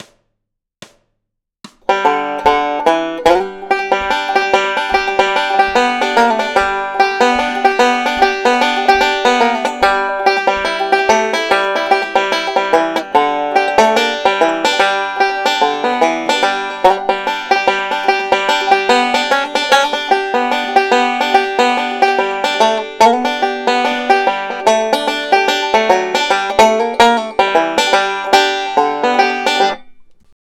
uses forward roll in a new way